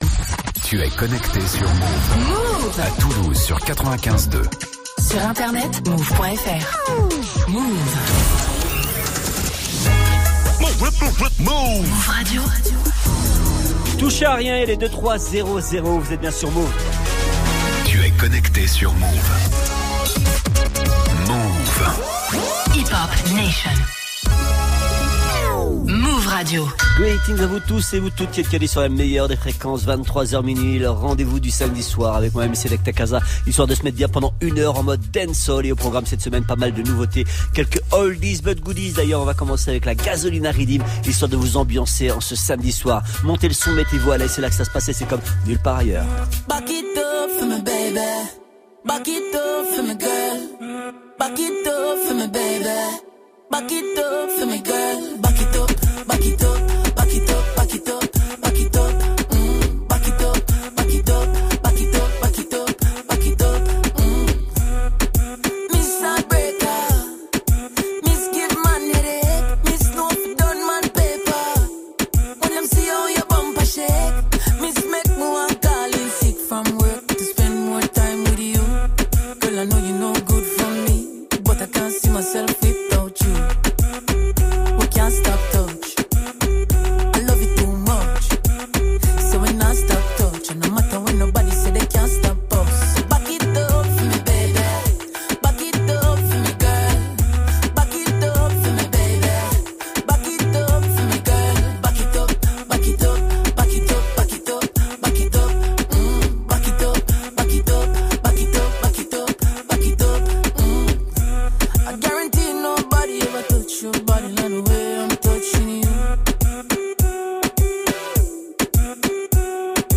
très dansante
tune tranchante